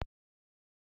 click4.ogg